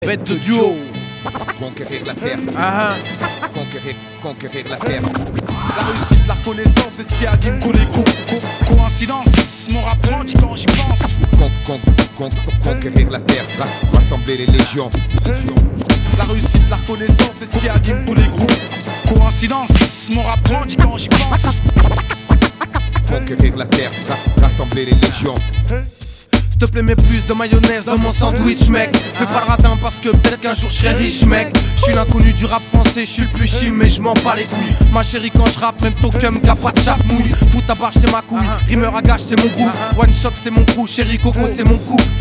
• Качество: 320, Stereo
саундтреки
качающие
французский рэп